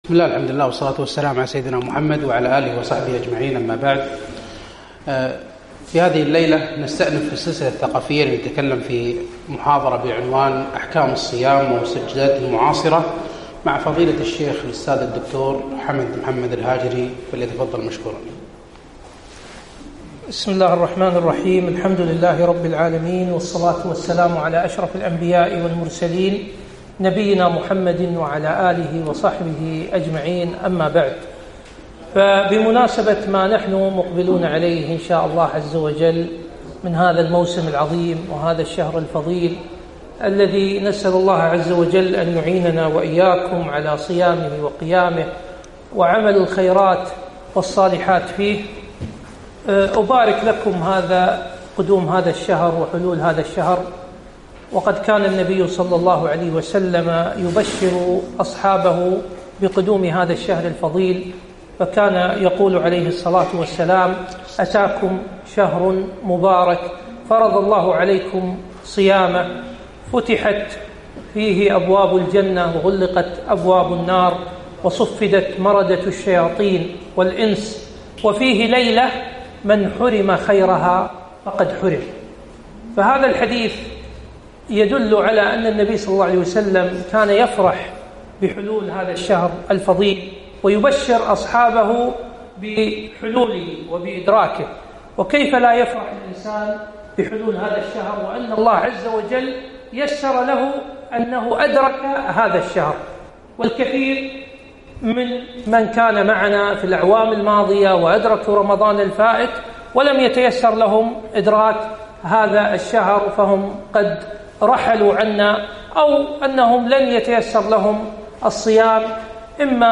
محاضرة - أحكام الصيام ومستجداته المعاصرة